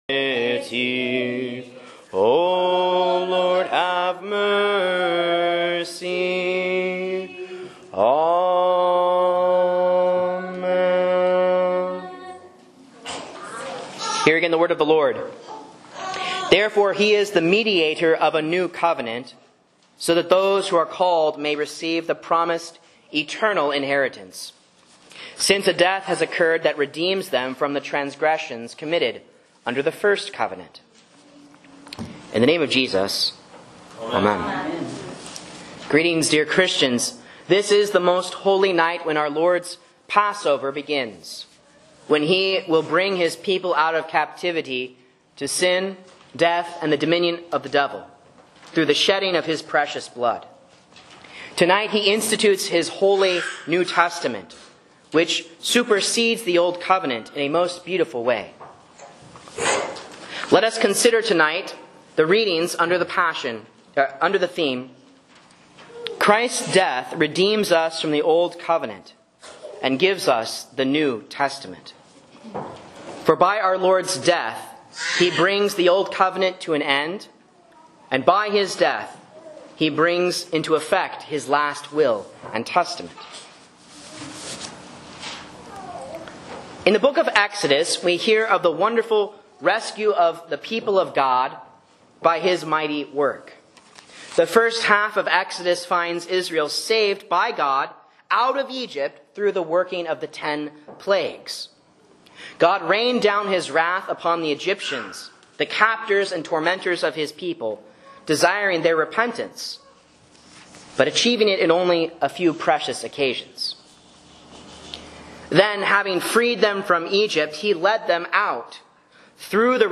A Sermon on Exodus 24:7-8 and Matthew 26:26-28 for Maundy Thursday (A)